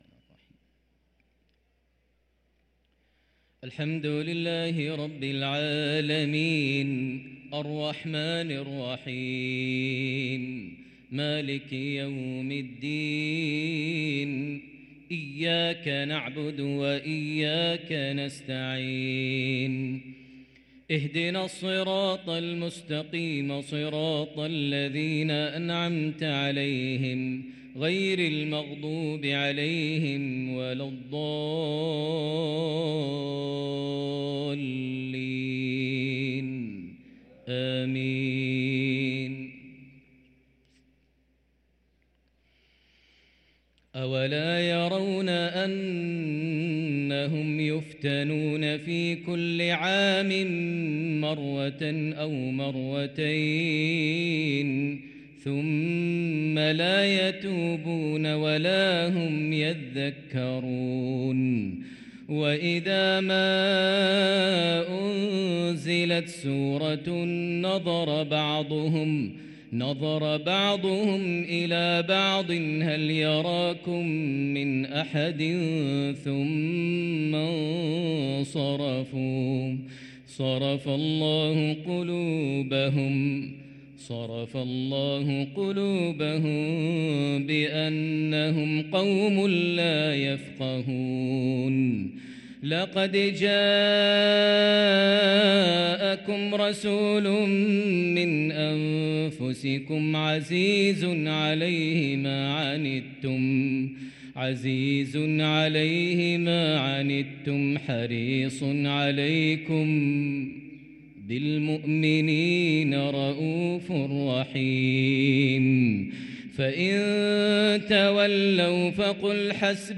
صلاة المغرب للقارئ ماهر المعيقلي 11 رجب 1444 هـ